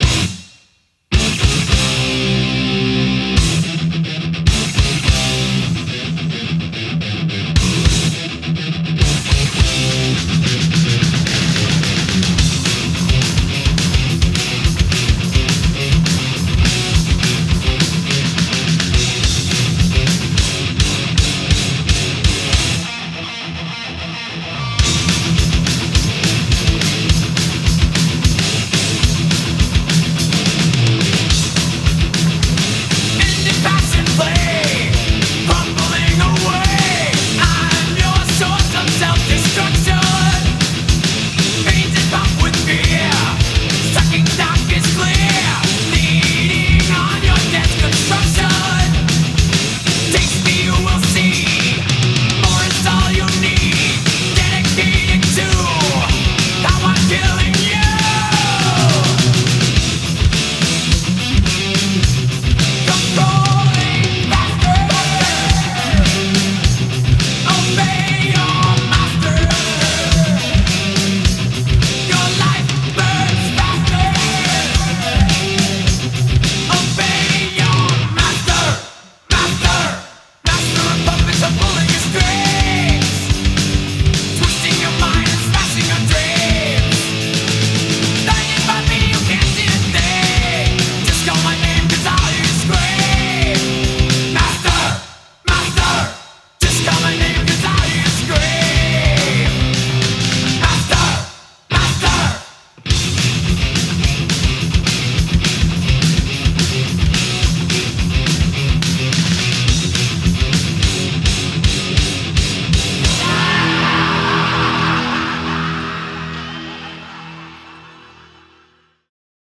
BPM215
Audio QualityPerfect (High Quality)
from the legendary thrash metal band